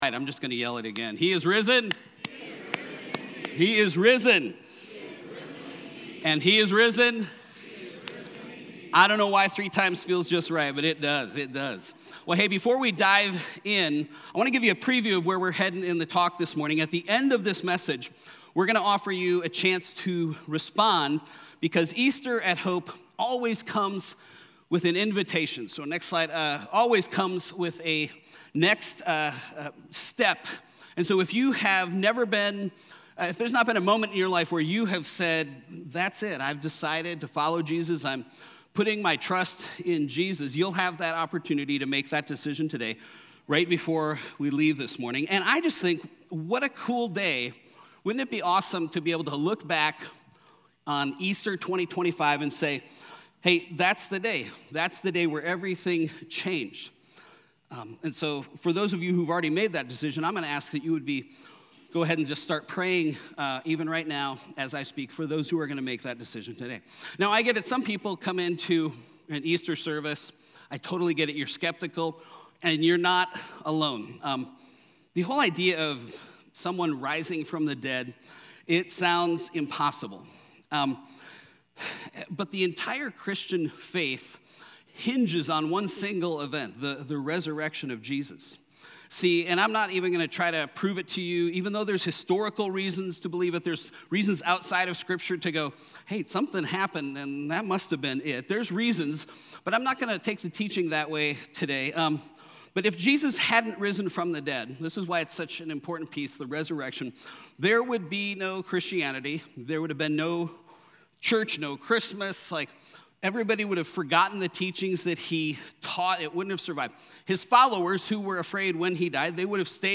Weekly messages from HOPE Covenant Church in Chandler AZ